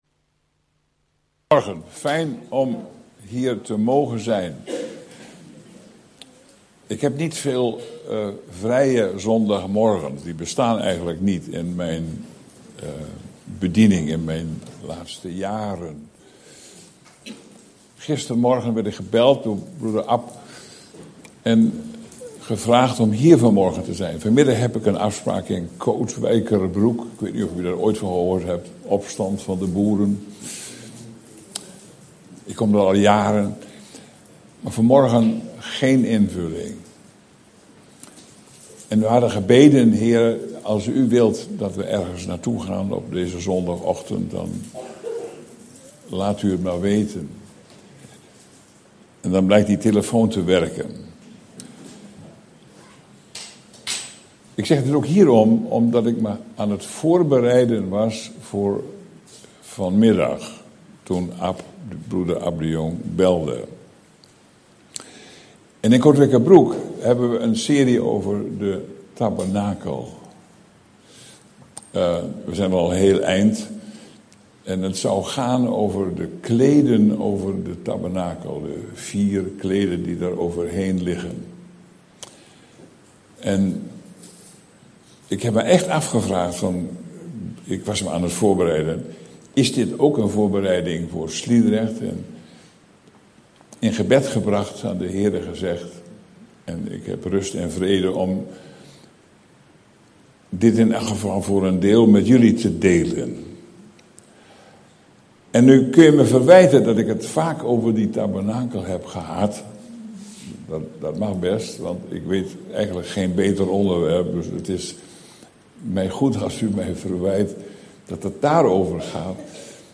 In de preek aangehaalde bijbelteksten (Statenvertaling)Exodus 26:1414 Gij zult ook voor de tent een deksel maken van roodgeverfde ramsvellen, en daarover een deksel van dassenvellen.